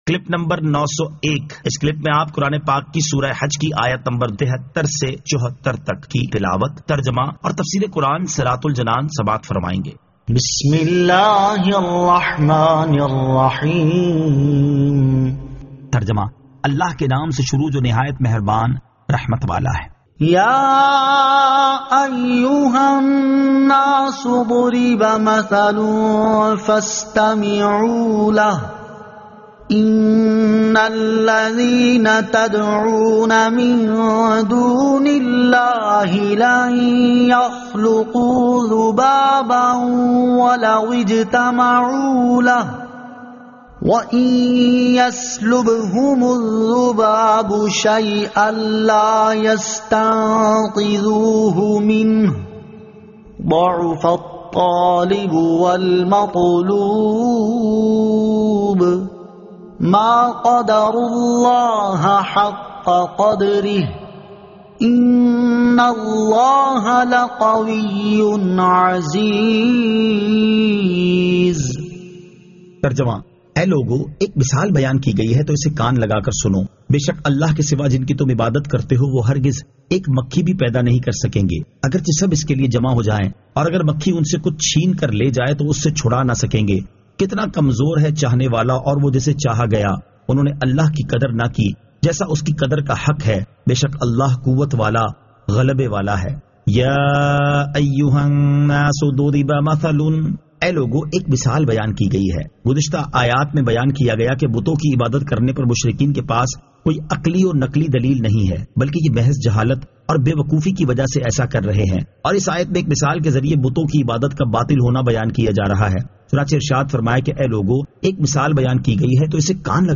Surah Al-Hajj 73 To 74 Tilawat , Tarjama , Tafseer